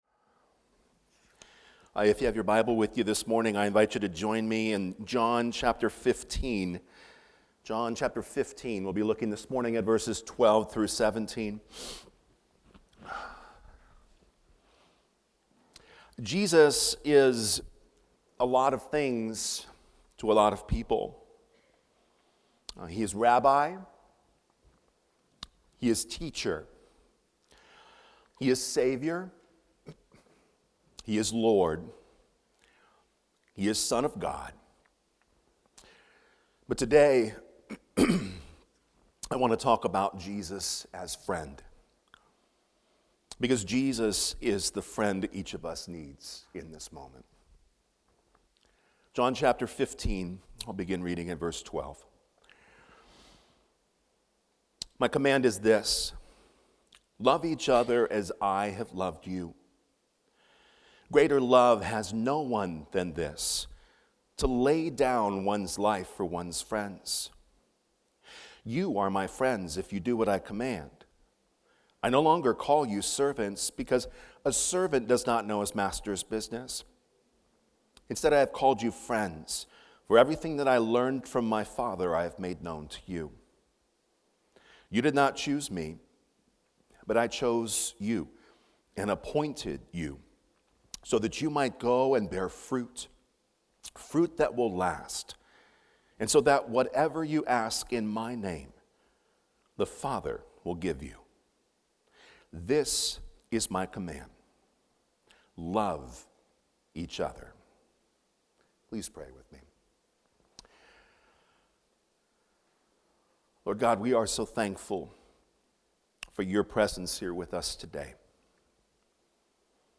FRIENDSHIP WITH JESUS | Fletcher Hills Presbyterian Church